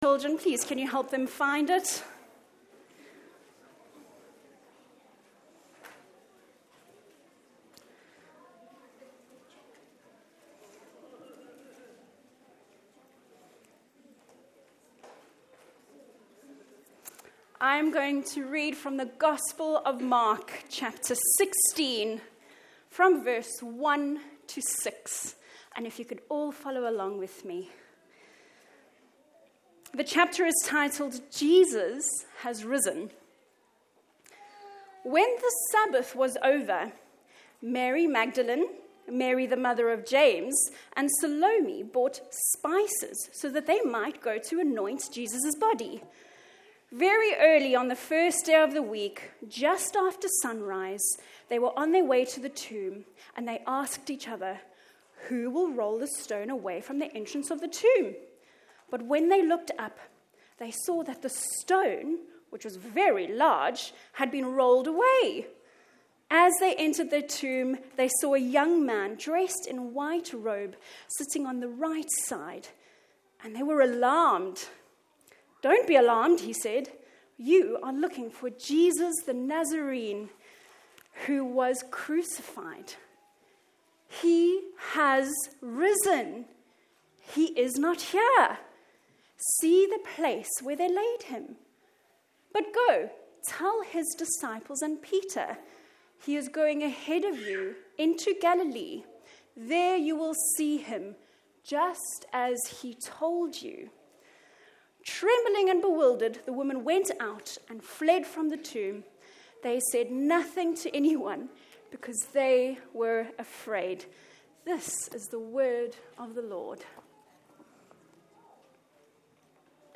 Easter Sunday 2025